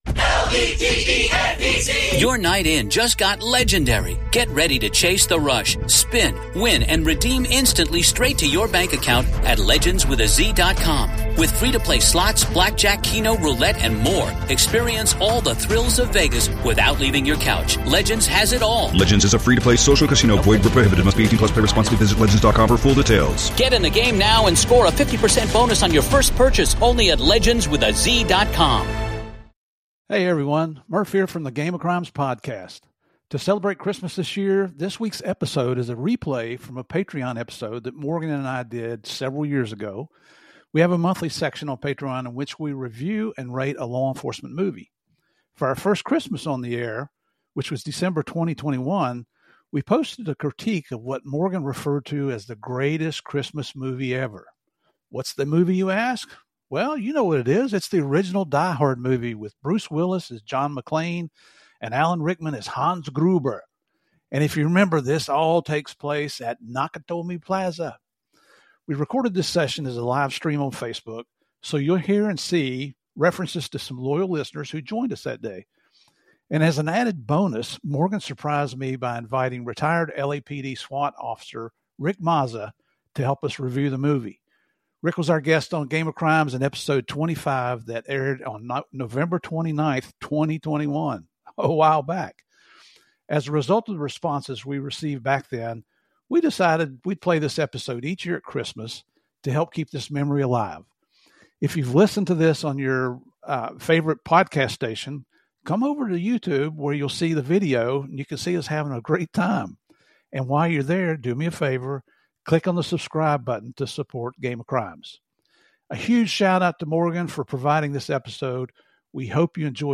Either way, this is a lively holiday special that has become a yearly tradition on Game of Crimes.
This trio’s conversation is filled with light-hearted humor, nostalgia, and an appreciation for the film's impact on pop culture.